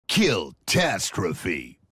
Play, download and share halo reach killtastrophe voice original sound button!!!!
halo-reach-killtastrophe-voice.mp3